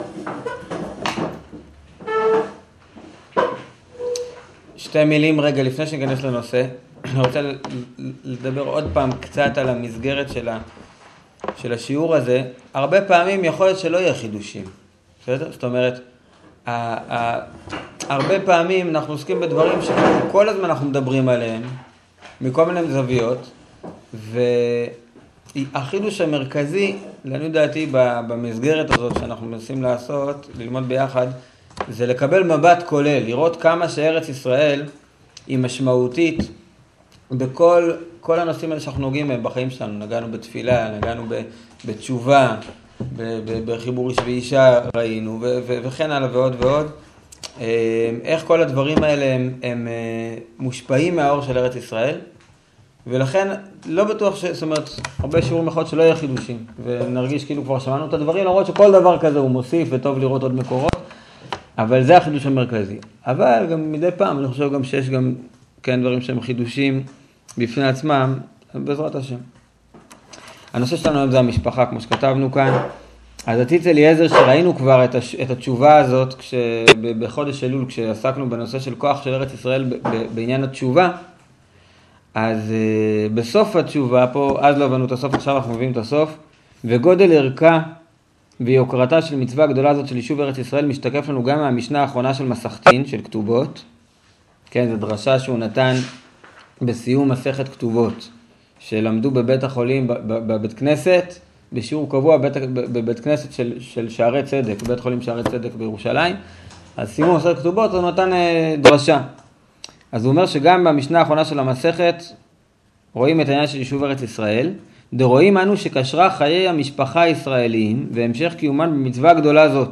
שיעור המשפחה